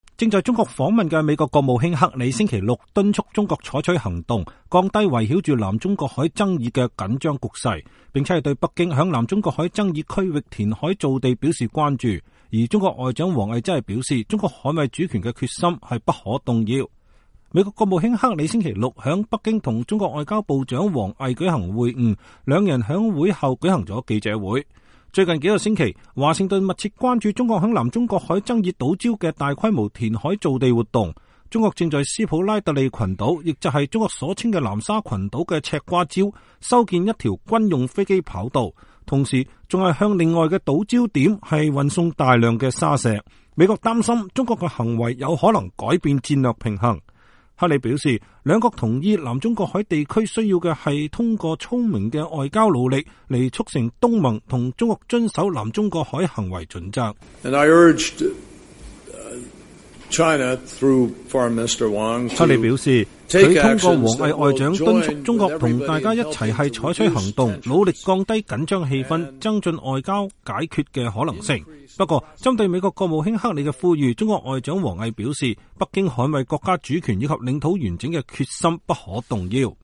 正在中國訪問的美國國務卿克里同中國外長王毅共同舉行了新聞發布會